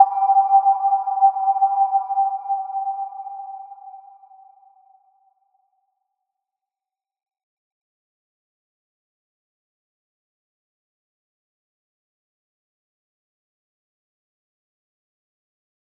Little-Pluck-G5-mf.wav